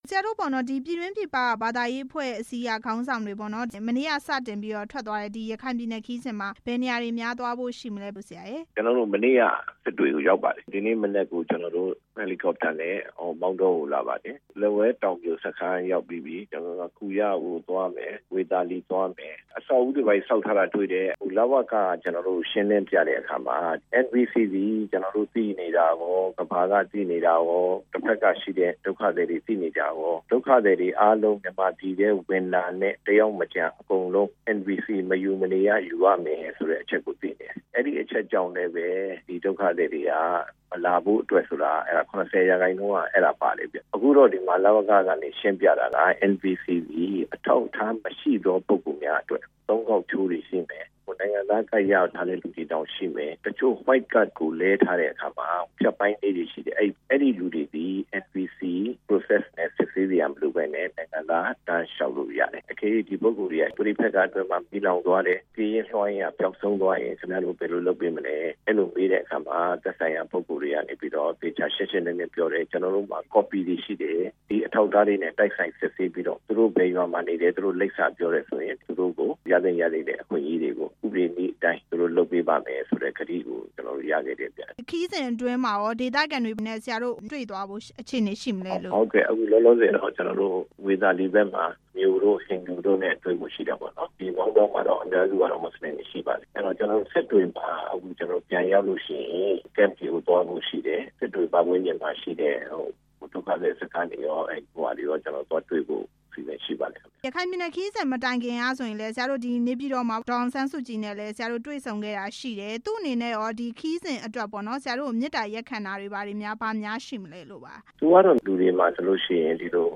ဘာသာပေါင်းစုံအဖွဲ့ရဲ့ ရခိုင်ခရီးစဉ်အကြောင်း မေးမြန်းချက်